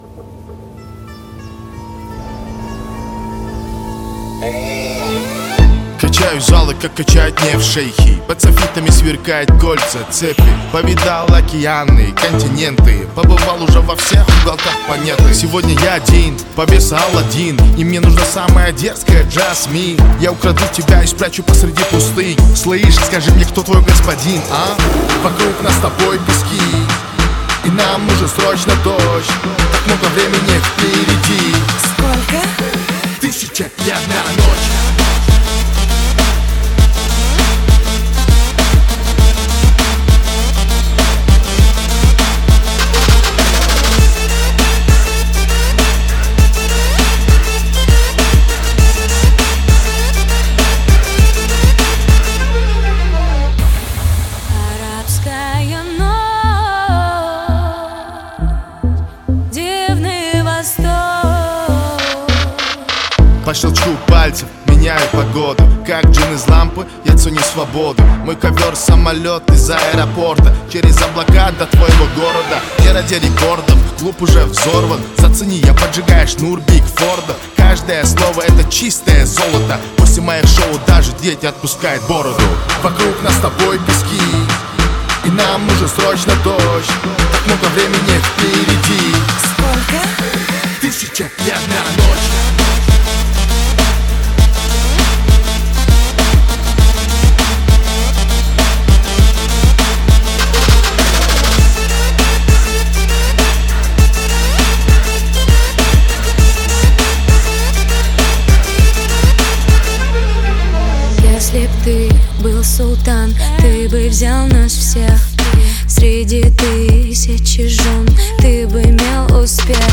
• Категория: Рэп Треки